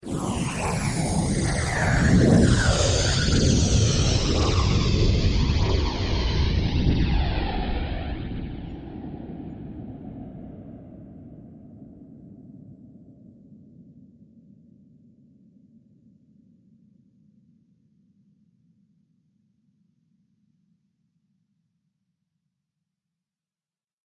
喷气式发动机循环
描述：五秒钟的未经过滤的发动机噪音（可循环），来自野马C510喷气式空中出租车的驾驶员座位。
标签： 喷气发动机 噪声 飞机 机舱噪声 发动机
声道立体声